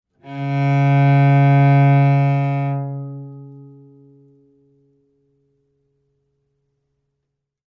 Звуки виолончели
Настройка звука виолончели